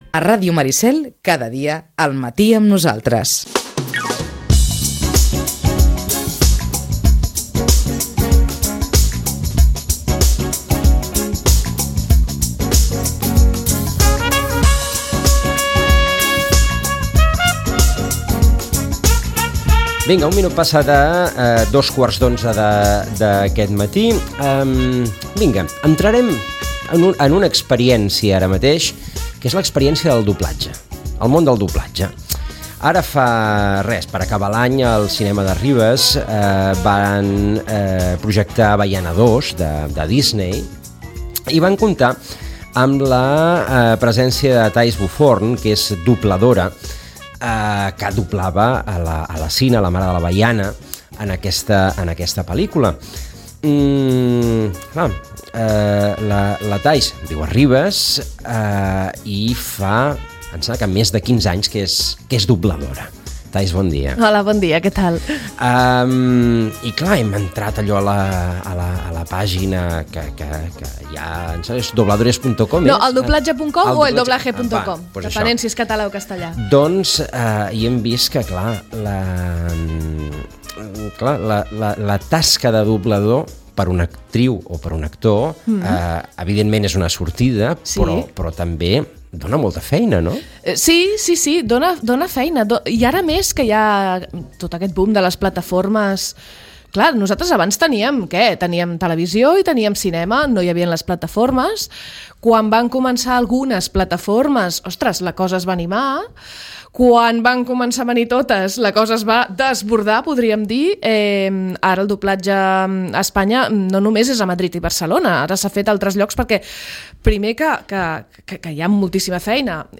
Ràdio Maricel. Emissora municipal de Sitges. 107.8FM. Escolta Sitges.
Hem parlat amb ella.